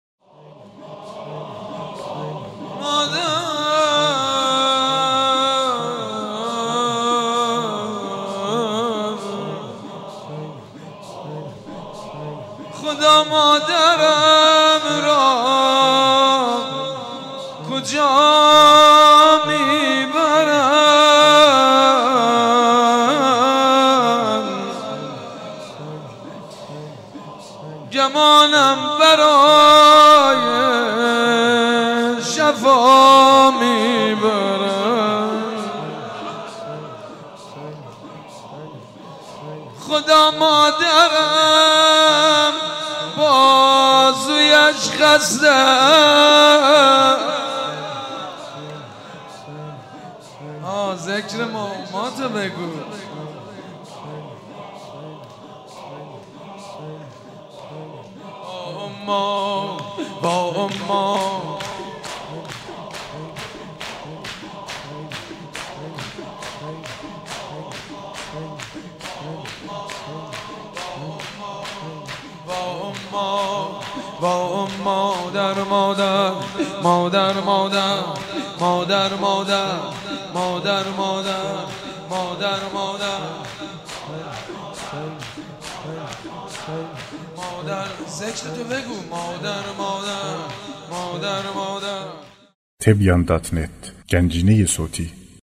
سینه زنی، شهادت حضرت زهرا(س